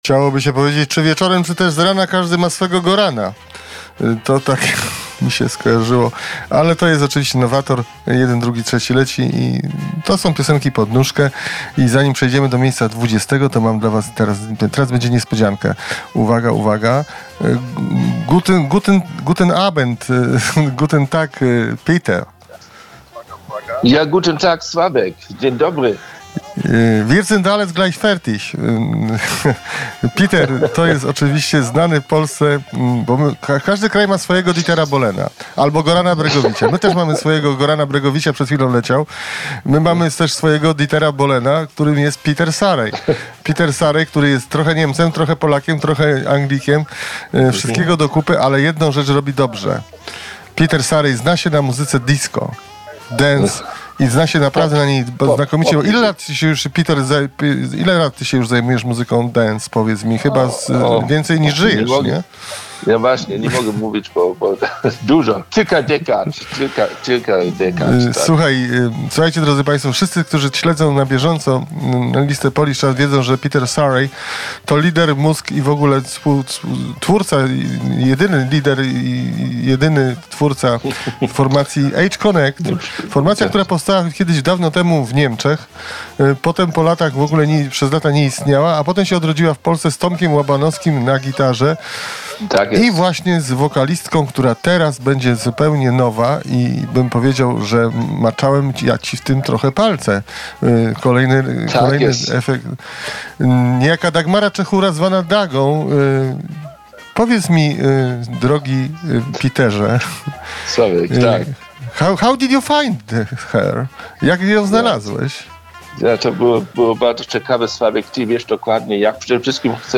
Listen to the Exclusive Interview